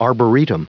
Prononciation du mot arboretum en anglais (fichier audio)
Prononciation du mot : arboretum